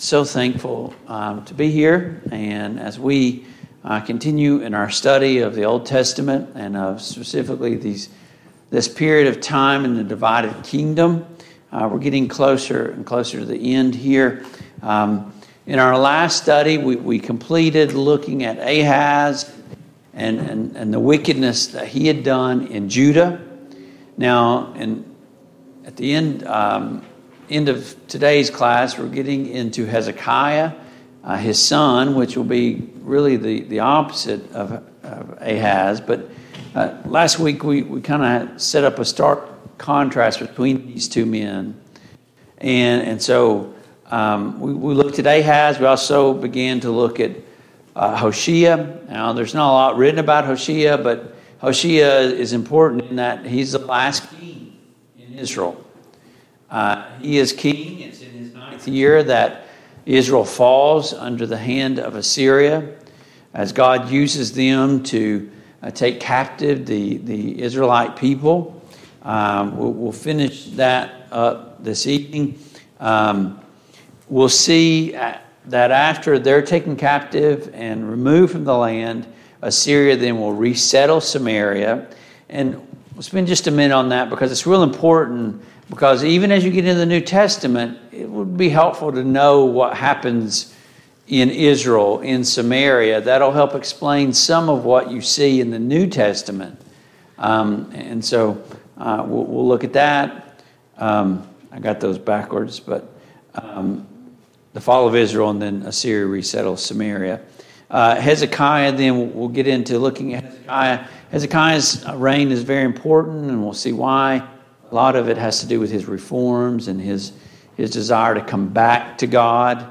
Passage: 2 Kings 17 Service Type: Mid-Week Bible Study Download Files Notes « Don’t miss the warning signs! 1.